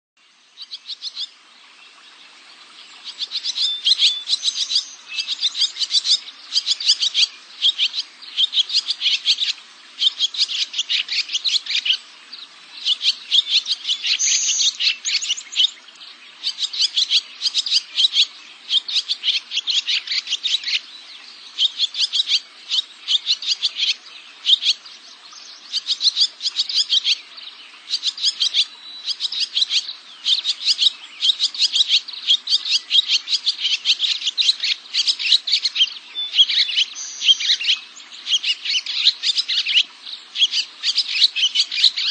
Barn Swallow
Bird Sound
Song: a twittery series of squeaky notes, often with dry rattle in the middle.
BarnSwallow.mp3